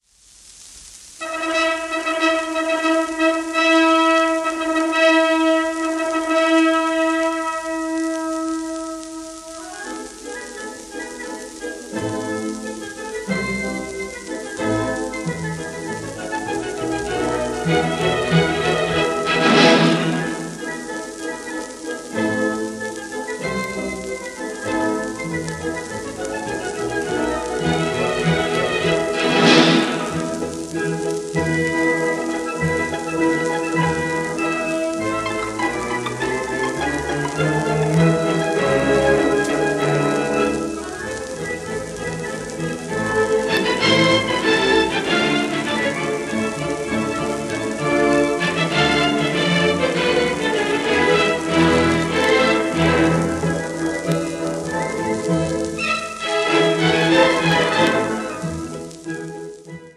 1930年録音